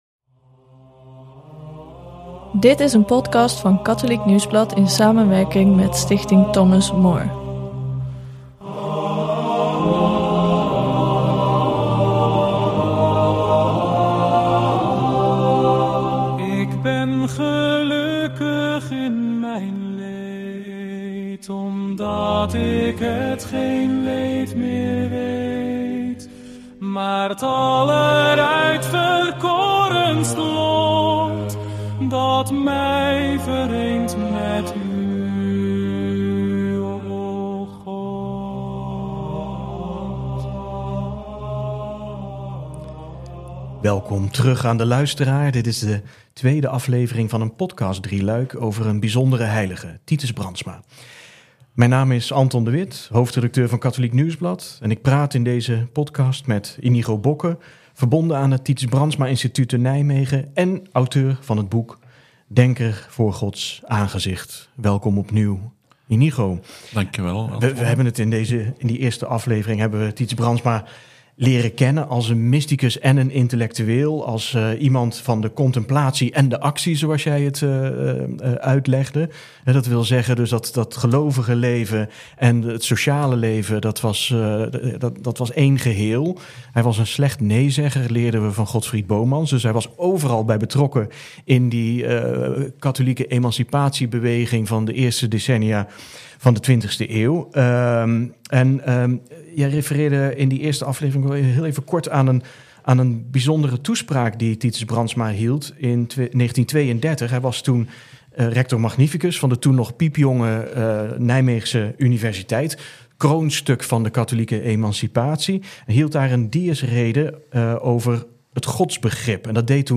Een podcast van Katholiek Nieuwsblad i.s.m. Stichting Thomas More Wie was de heilige Titus Brandsma echt en wat kan zijn gedachtegoed ons vandaag leren? In dit driedelige gesprek duikt KN in het leven en denken van de karmeliet, mysticus, intellectueel en journalist.